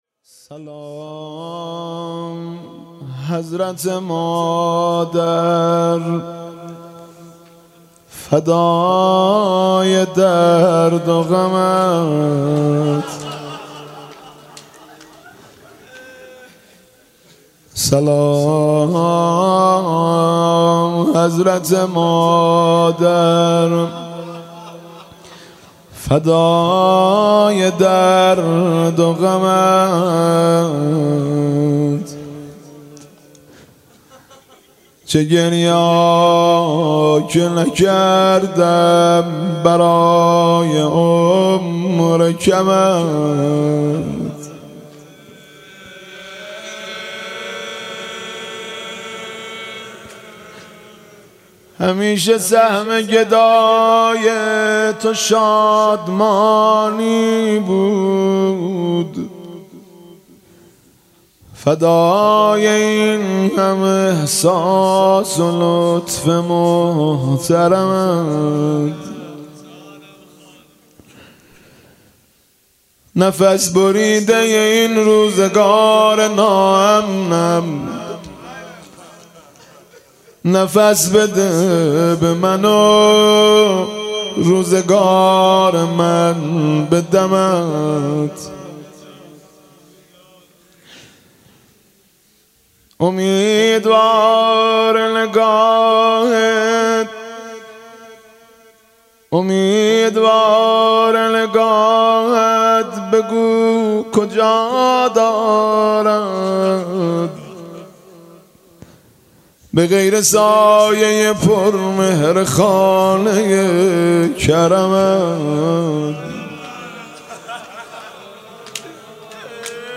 «سلام حضرت مادر» مداحی مهدی رسولی را به مناسبت ایام فاطمیه بشنوید.